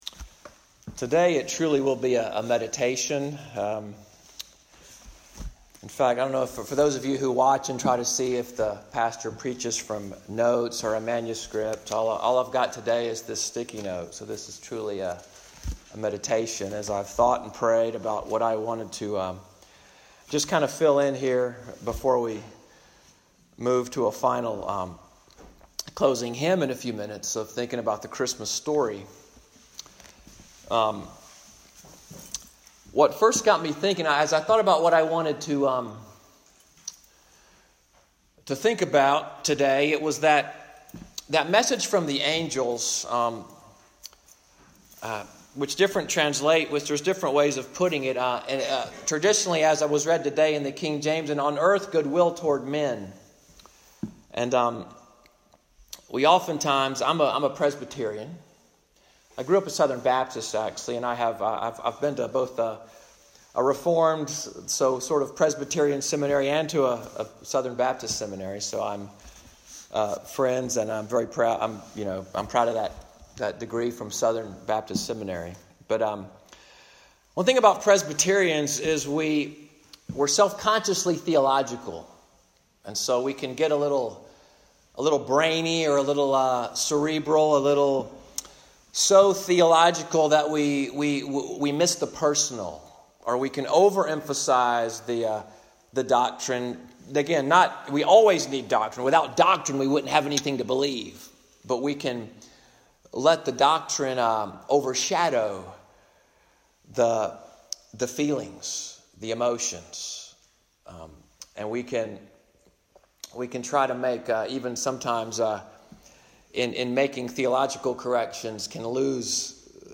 Xmas_2018_meditation.mp3